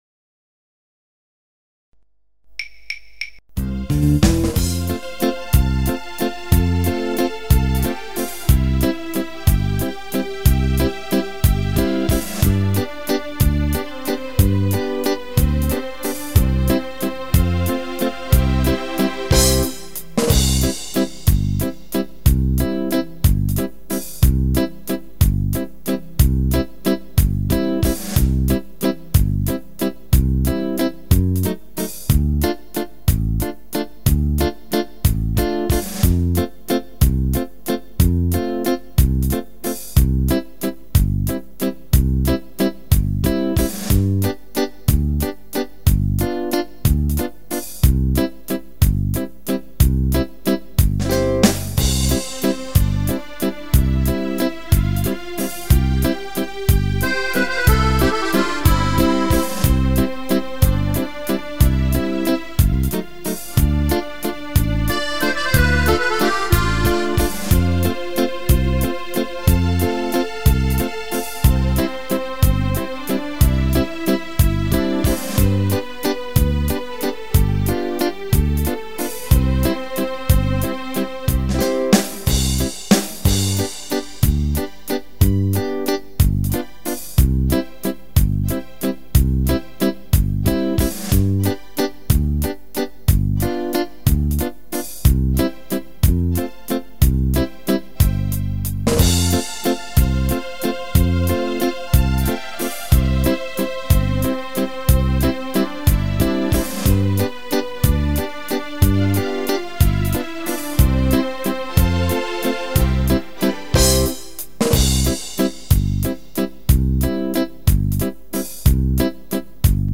Canzoni e musiche da ballo
Valzer